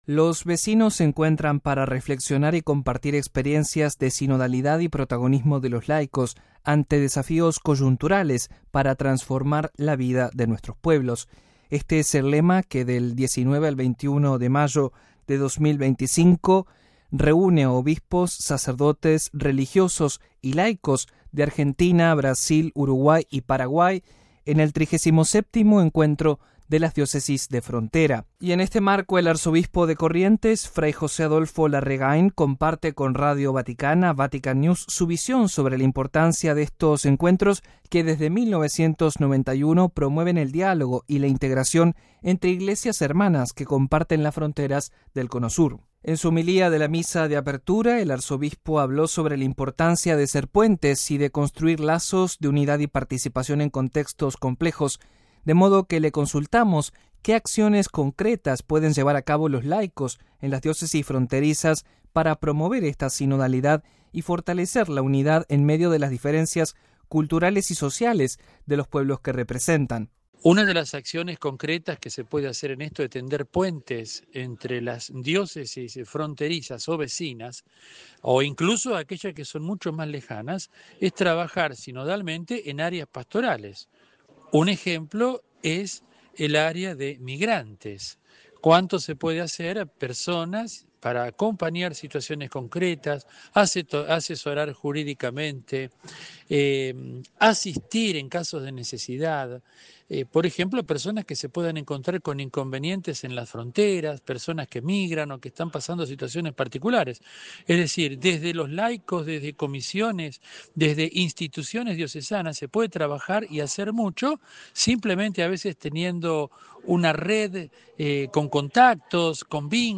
An interview with the Archbishop of Corrents